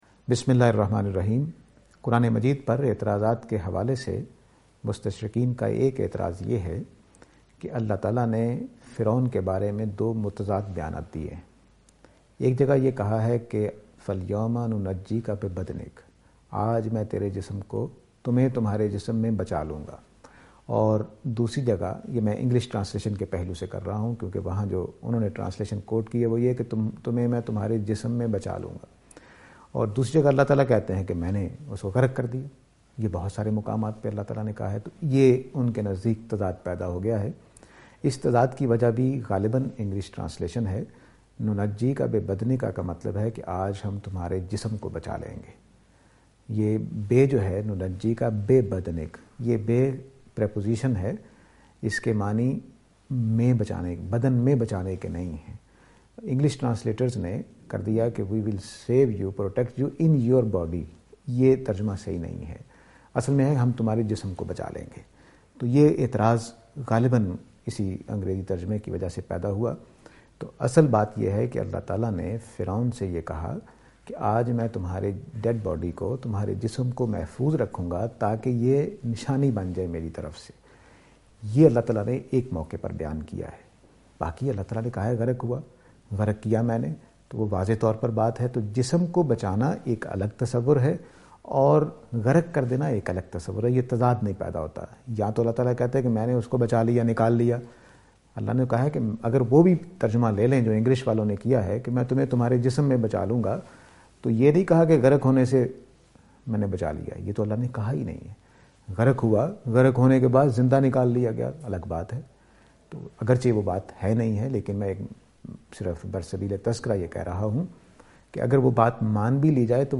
This lecture will present and answer to the allegation "Was pharaoh (Fir’aoun) immersed?".